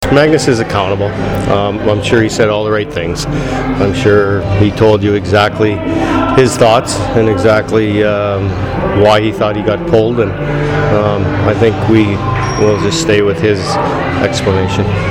post-game interviews